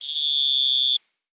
cricket_chirping_solo_02.wav